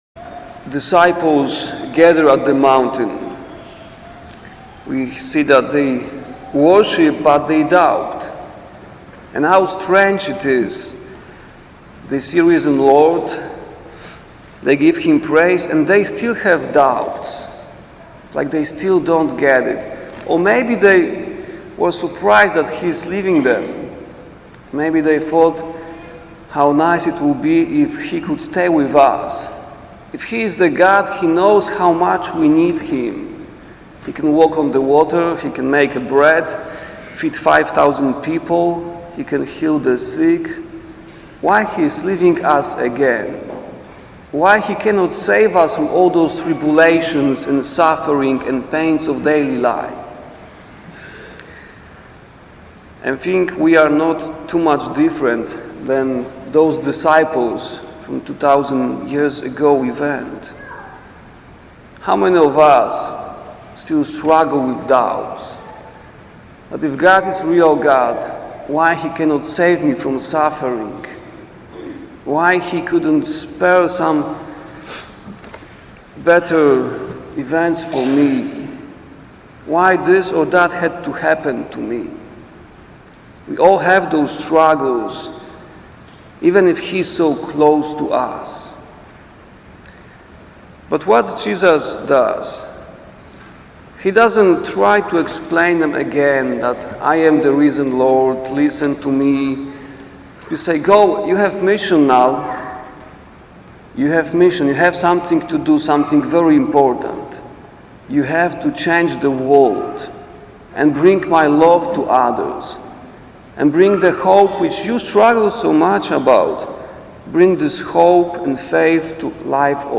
homily-for-the-ascension-of-the-lord.mp3